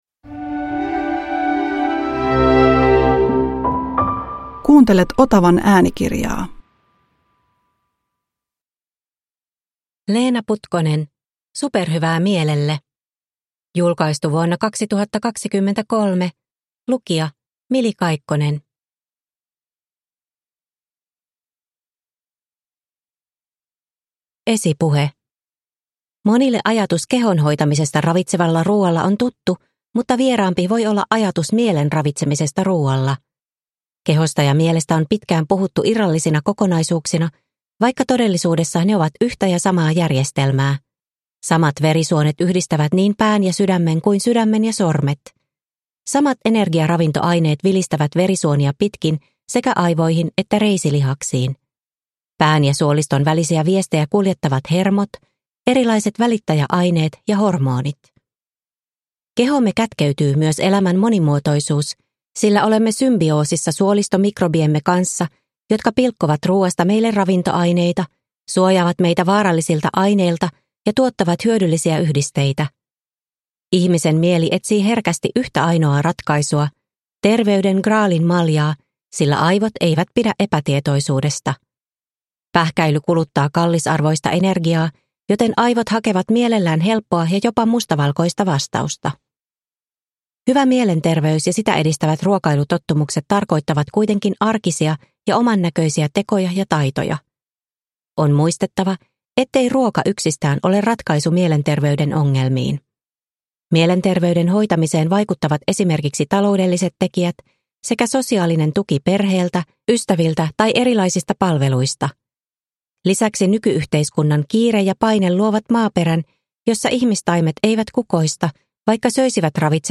Superhyvää mielelle – Ljudbok – Laddas ner